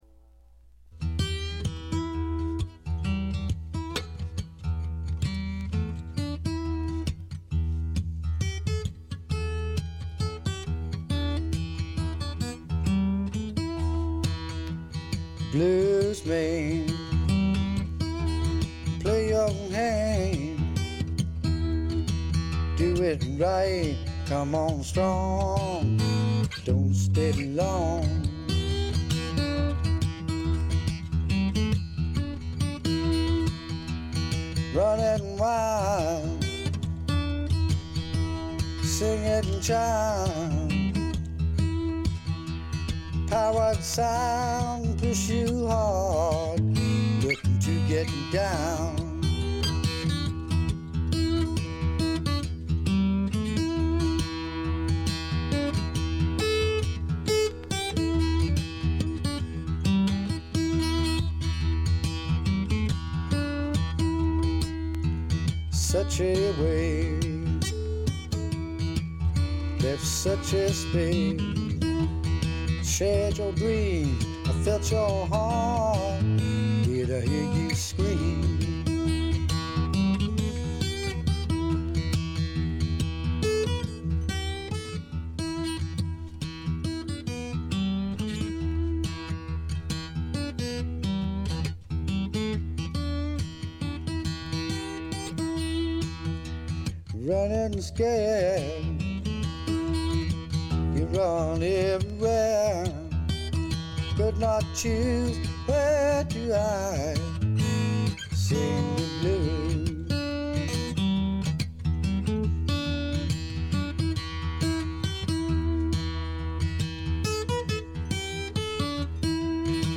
部分試聴ですがわずかなノイズ感のみ。
試聴曲は現品からの取り込み音源です。